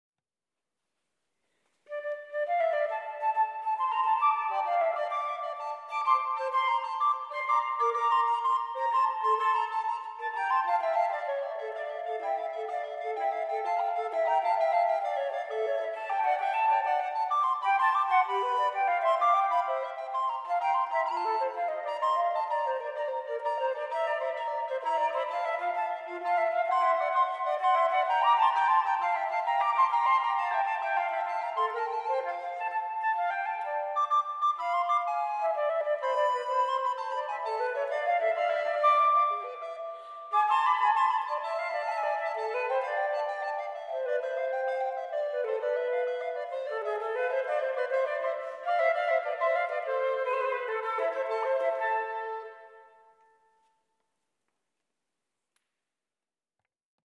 Blockflöten
Querflöten
Allegro
Die Klänge der beiden Flöten können verschmelzen, sich in ihrer Unterschiedlichkeit ergänzen, sich tragen und dann wieder klingt jede Flöte so, wie sie eben auf ihre Weise klingt.